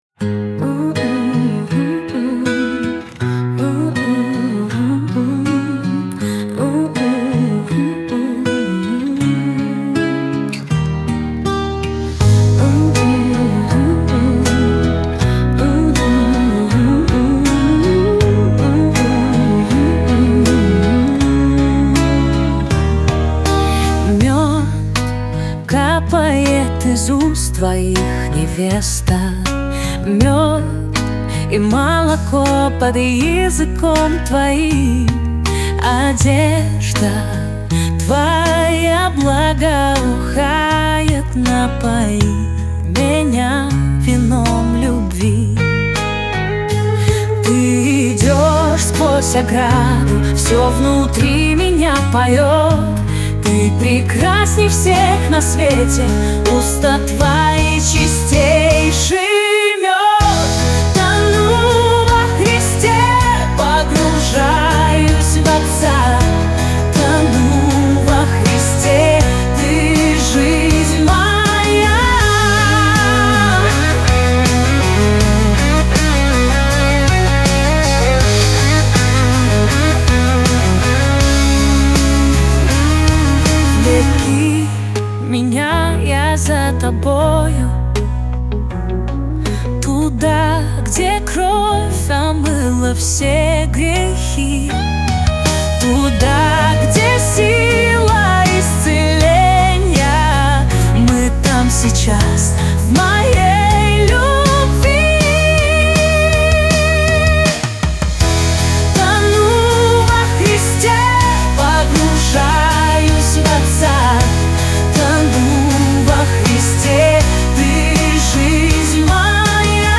песня ai
105 просмотров 92 прослушивания 7 скачиваний BPM: 80